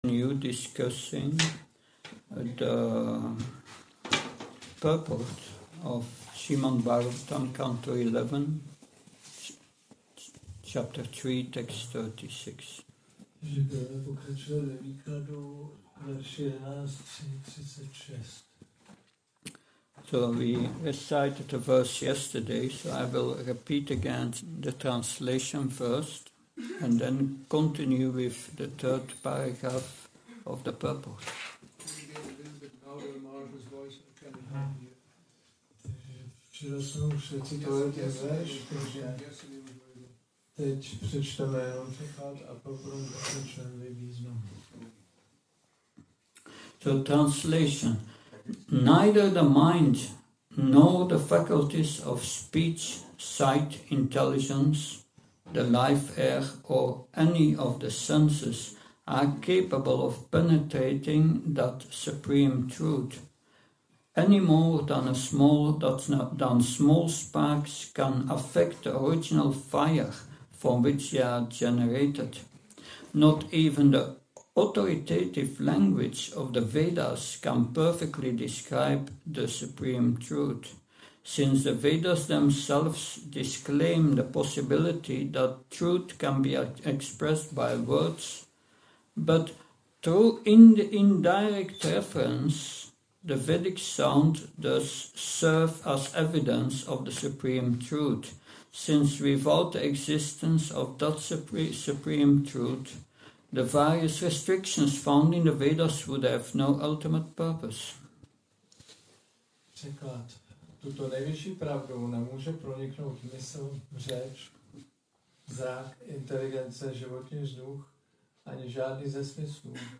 Přednáška SB-11.3.36